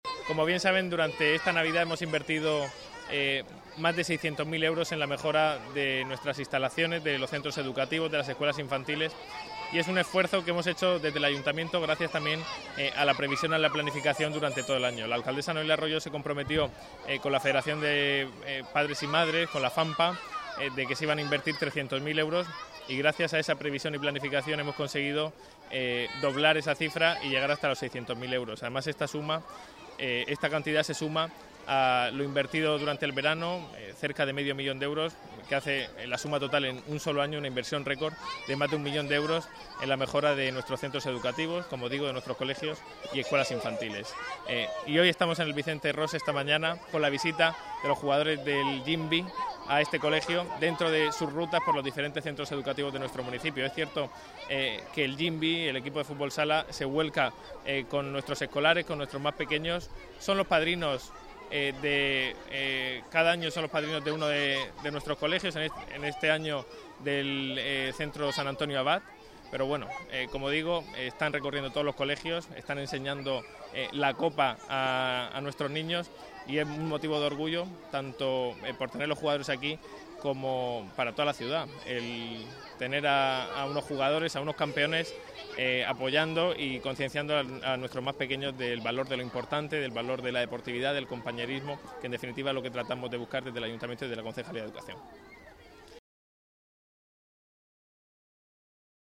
Enlace a Declaraciones de Nacho Jáudenes.